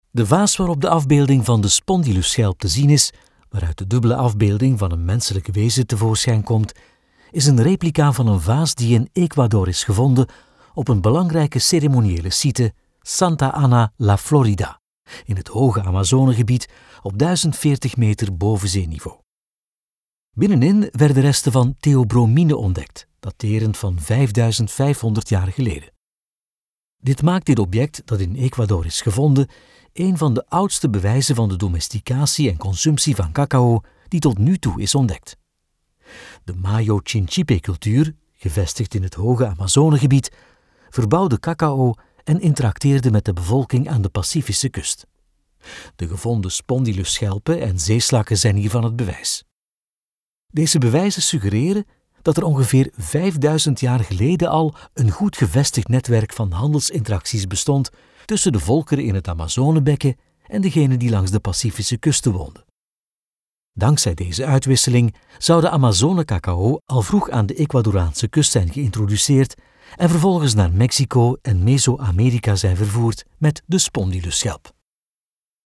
Male
Flemish (Native)
Corporate, Energetic, Engaging, Friendly, Natural, Smooth
Commercial.mp3
Microphone: Neumann U89 + Brauner Phantom C + Sennheiser MKH 416